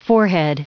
Prononciation du mot forehead en anglais (fichier audio)
Prononciation du mot : forehead